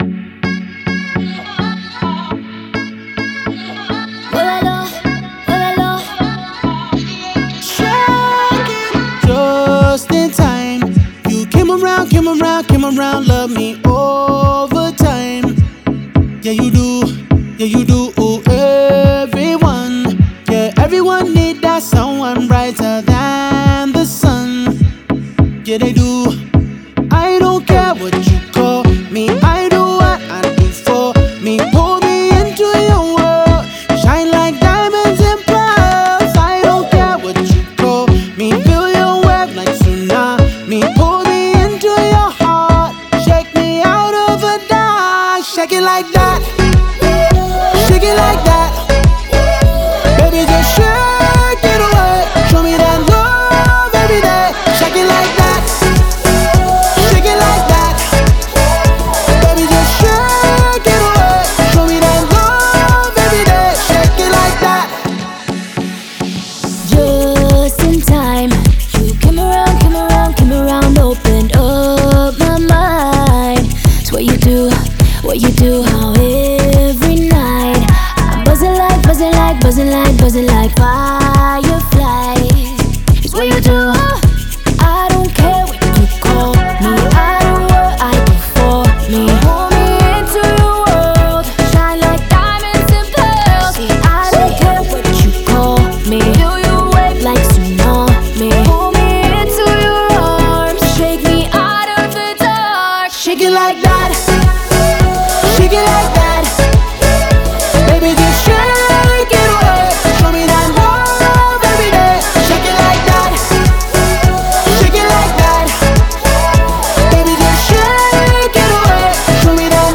это зажигательный трек в жанре поп и R&B